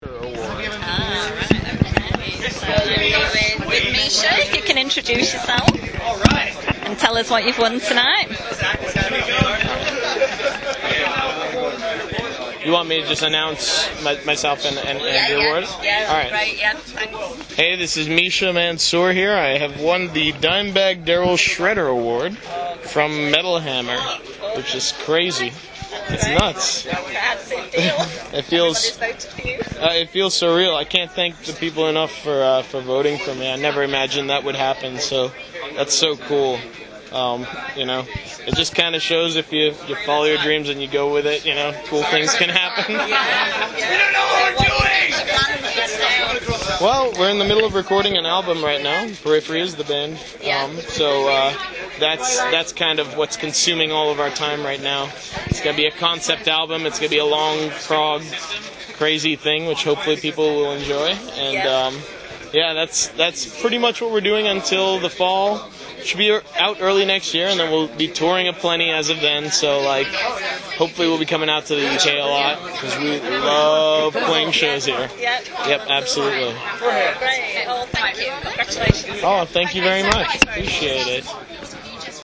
Interview With MISHA MANSOOR: Winner Of DIMEBAG DARRELL ‘Shredder’ Golden God Award
It was a very busy and warm Monday evening backstage in the media area of London’s Indig02 Arena, the location for Metal Hammer’s Golden Gods Awards 2014.